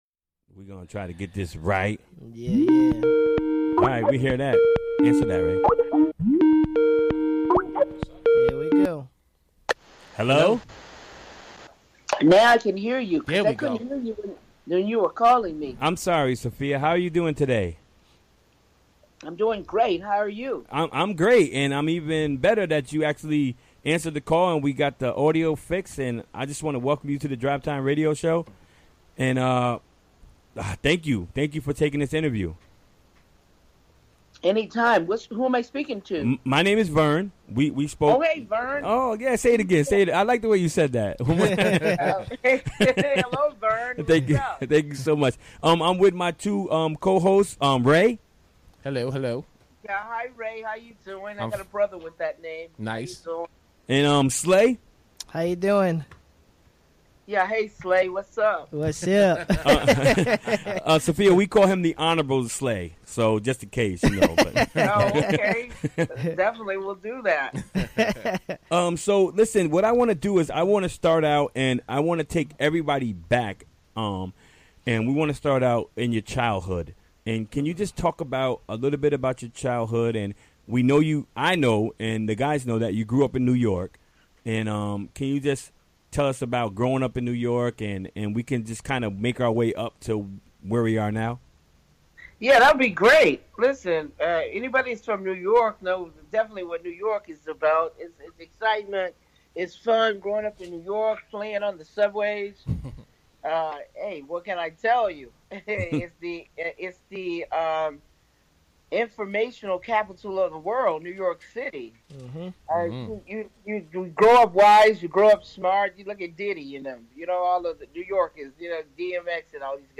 Recorded during the WGXC Afternoon Show Wednesday, January 17, 2018.